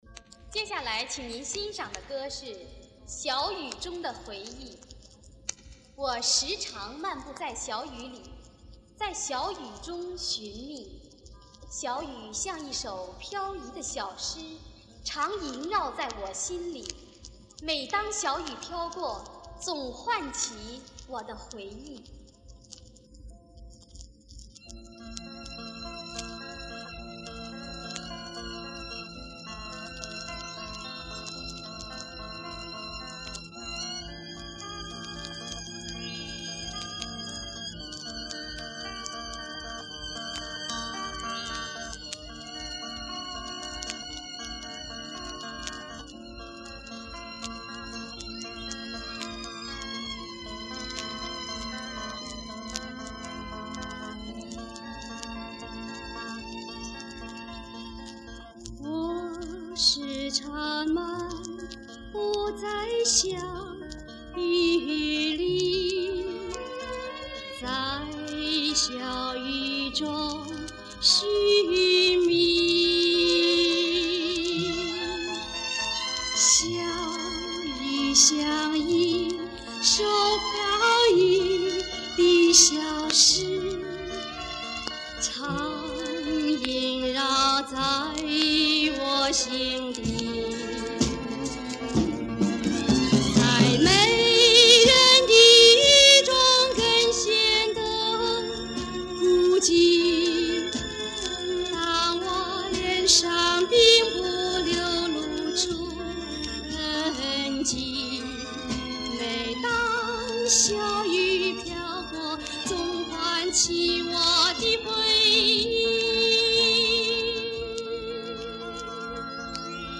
给你找到一个LP版本，音质差些